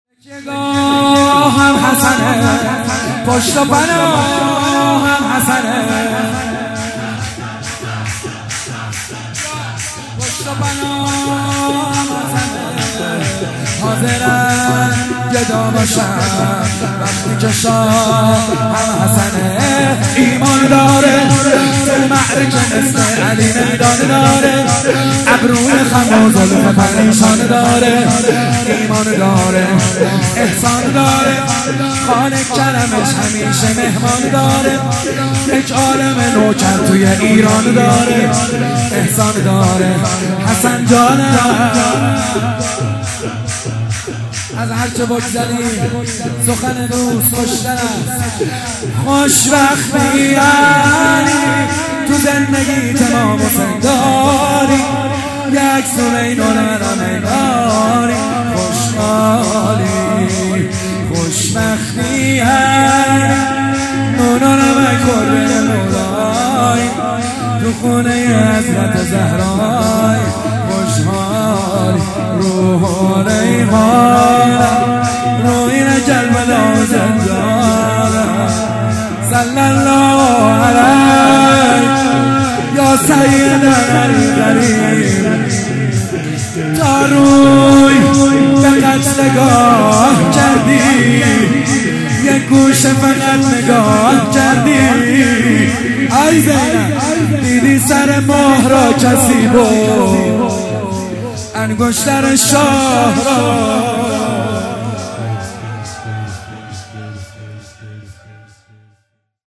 ولادت حضرت زینب سلام الله علیها
ولادت حضرت زینب سلام الله علیها97 شور محمد حسین حدادیان